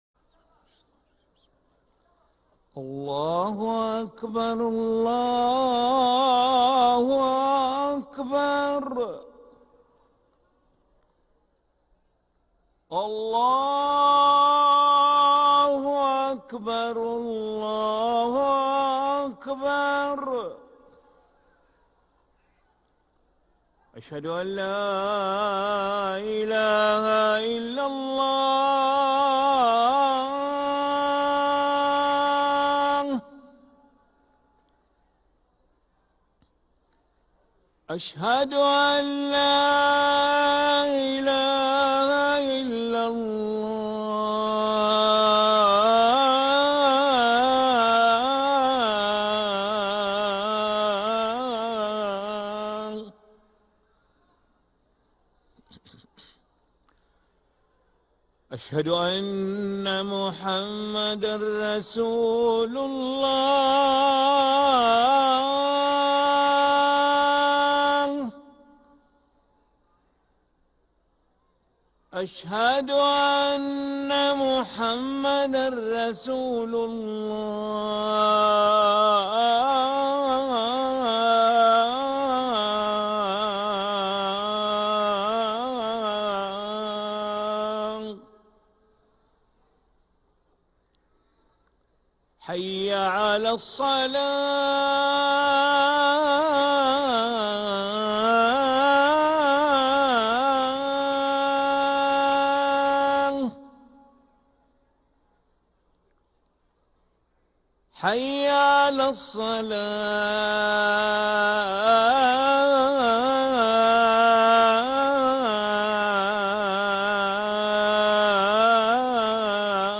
أذان العشاء للمؤذن علي ملا الخميس 30 ربيع الأول 1446هـ > ١٤٤٦ 🕋 > ركن الأذان 🕋 > المزيد - تلاوات الحرمين